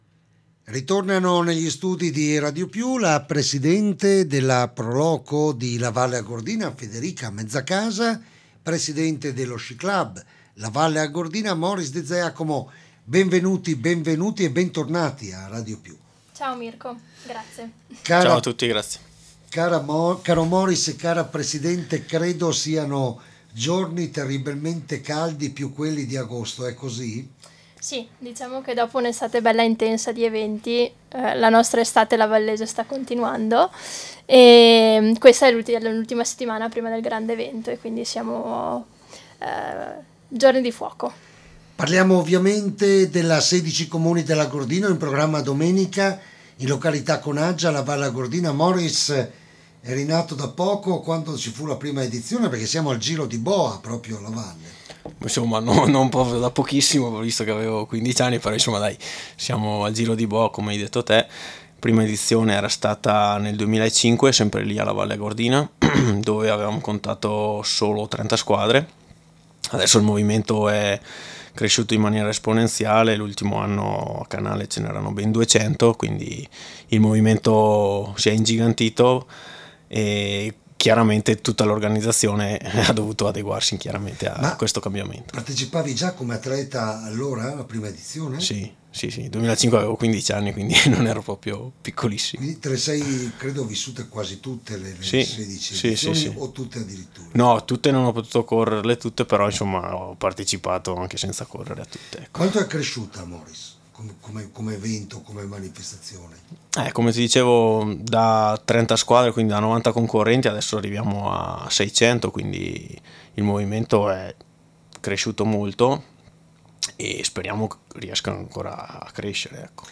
*** Dagli studi di RADIOPIU